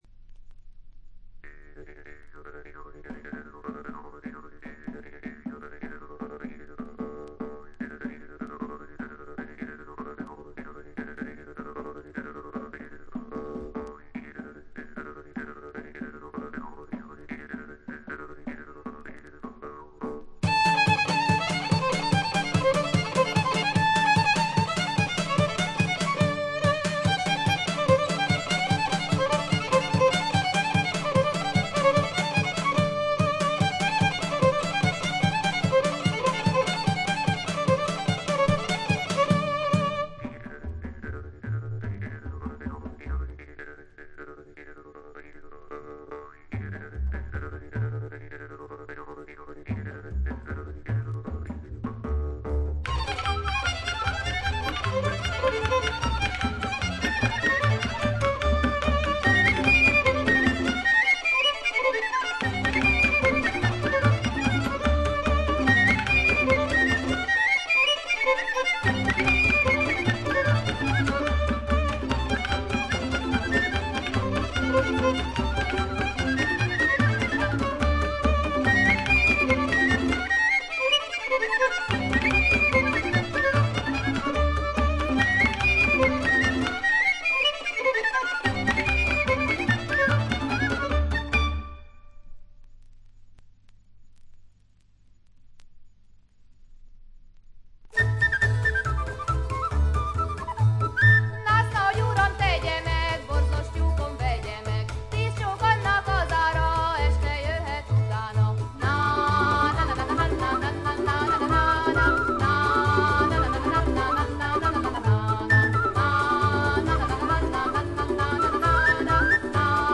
見た目よりちょっと劣り、細かなチリプチや散発的なプツ音は聴かれます。
ハンガリーの男女混成7−8人のトラッド・グループ。
エキゾチックな演奏に力強い女性ヴォーカルが響き合って得も言われぬ世界を描き出しています。
試聴曲は現品からの取り込み音源です。